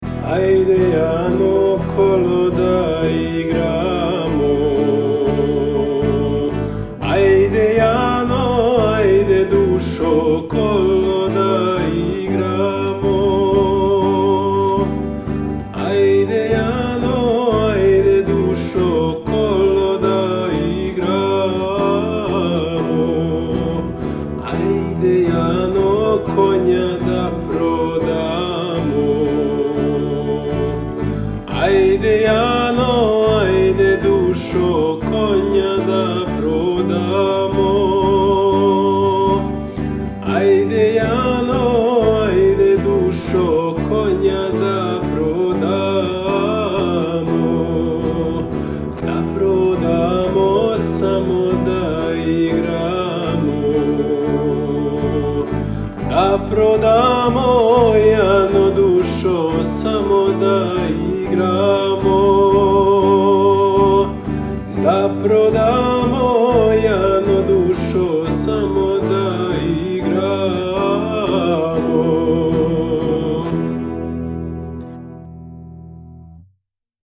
traditionelles Volkslied
Wir notieren die Noten, nehmen die Lieder auf und Muttersprachler:innen singen die Melodie ein.